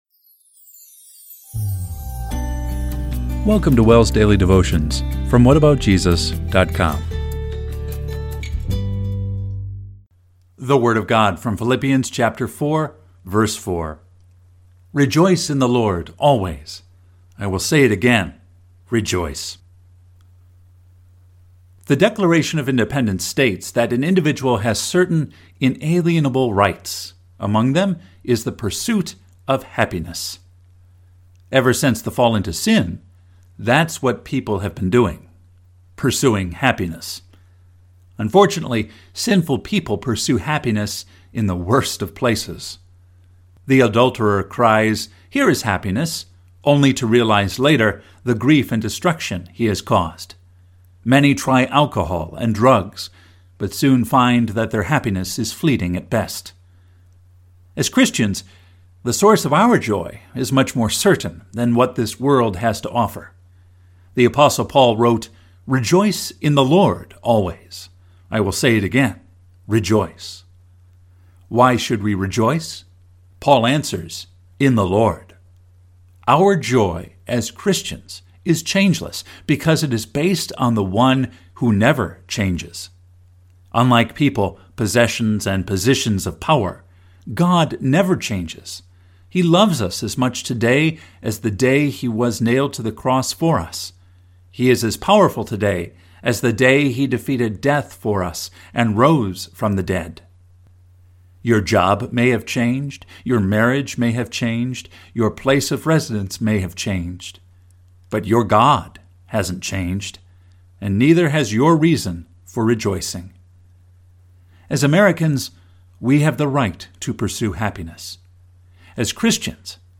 Daily Devotion – July 5, 2025